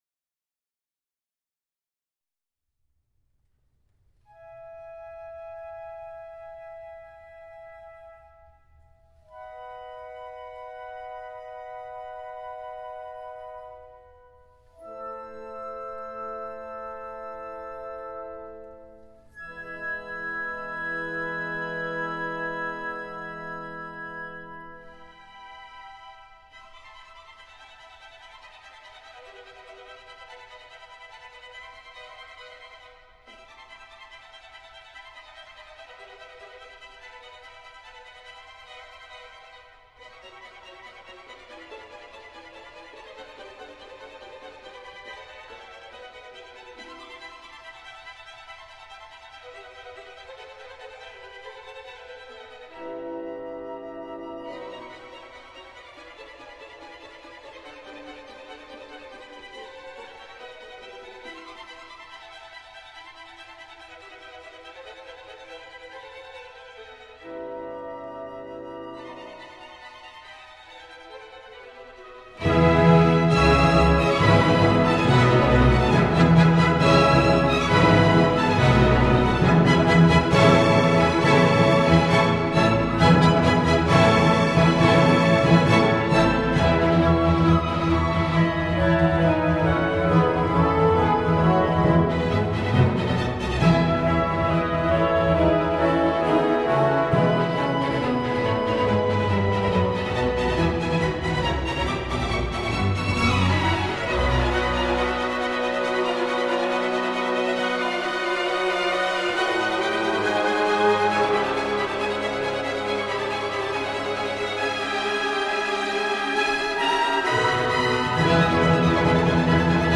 C’est une petite merveille de verve, de finesse et d’orchestration !